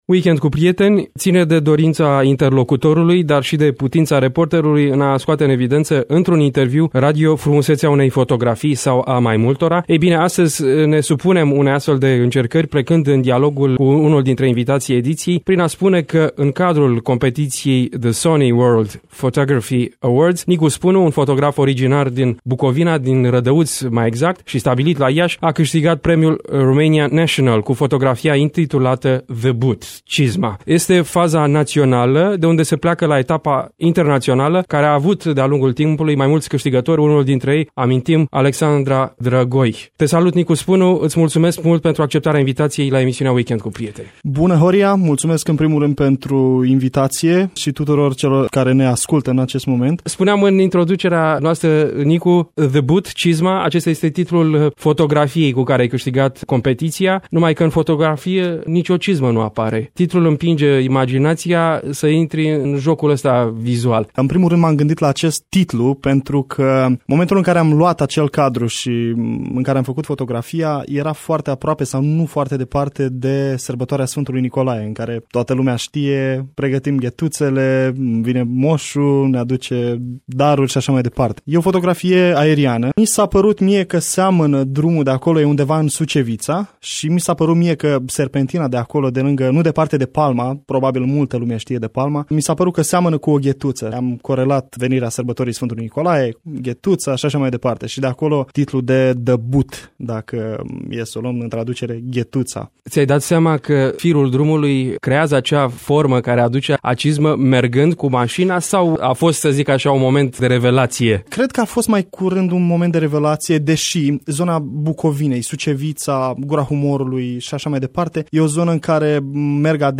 Ține de dorința interlocutorului, dar și de putința reporterului în a scoate în evindeță, într-un interviu radio, frumusețea, spectaculosul unei fotografii.